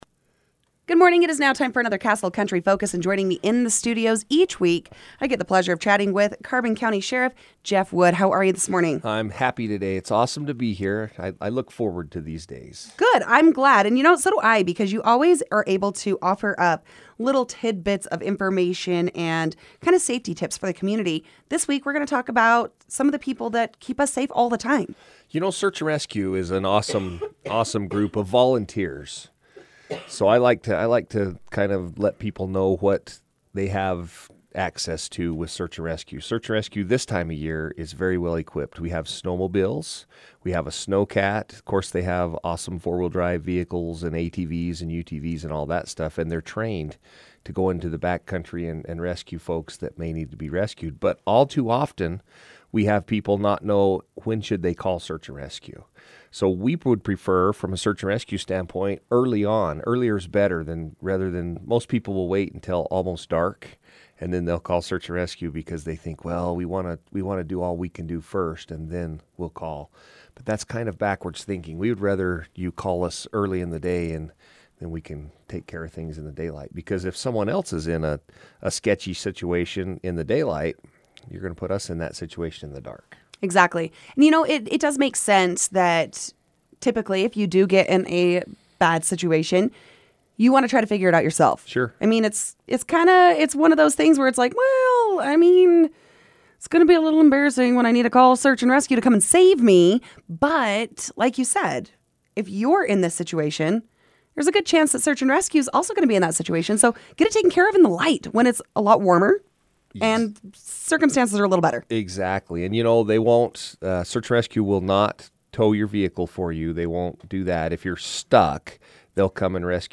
Carbon County Sheriff talks about Search and Rescue Team
Carbon County Sheriff Jeff Wood took time on his weekly update to talk about the team.